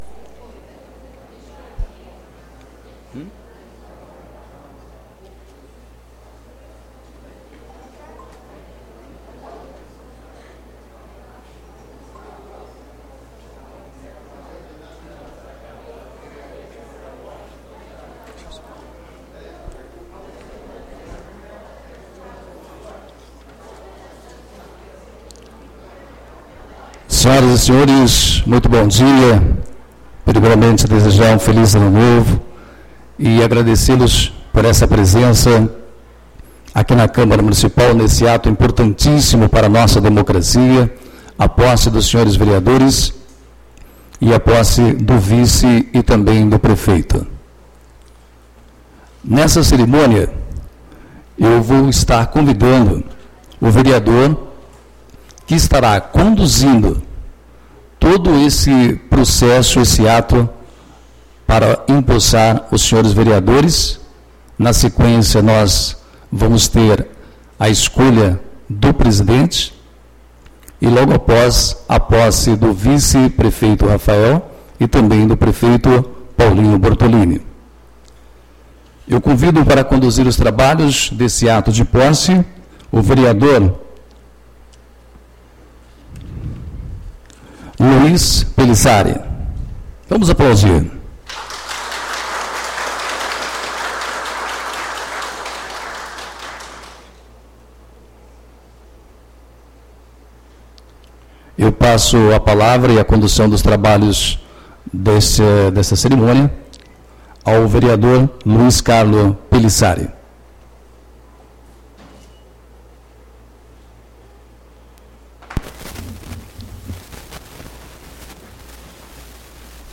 Sessão Solene de Posse Vereadores e Prefeito gestão 2021-2024